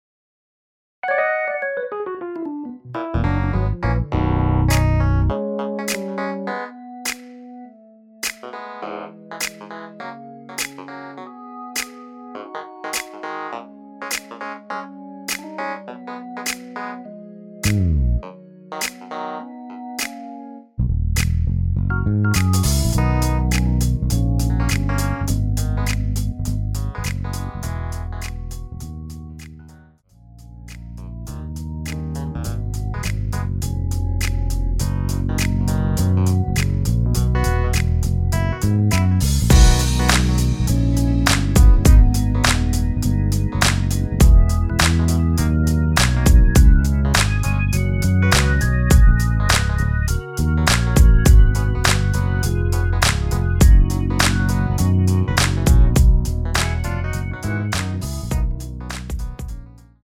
MR은 2번만 하고 노래 하기 편하게 엔딩을 만들었습니다.(본문의 가사와 코러스 MR 미리듣기 확인)
원키에서(-1)내린 MR입니다.
앞부분30초, 뒷부분30초씩 편집해서 올려 드리고 있습니다.
중간에 음이 끈어지고 다시 나오는 이유는